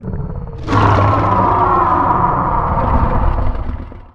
sound / monster2 / fire_dragon / attack1_1.wav
attack1_1.wav